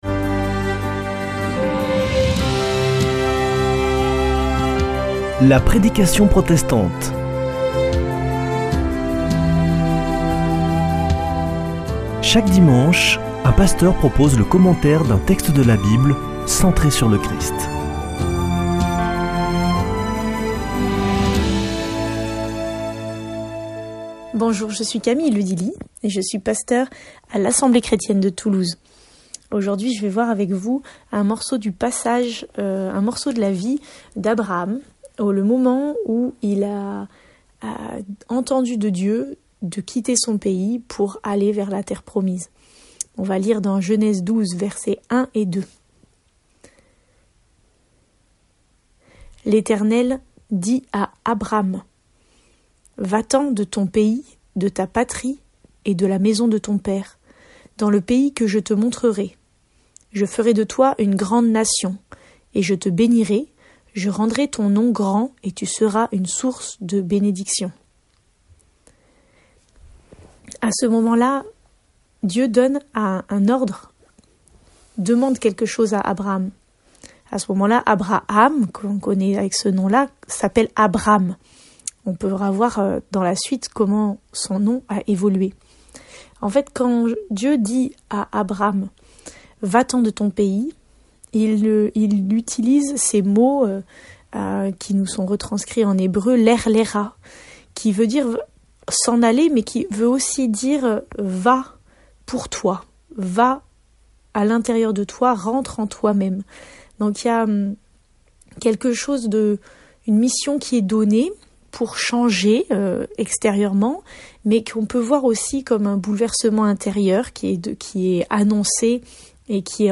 La prédication protestante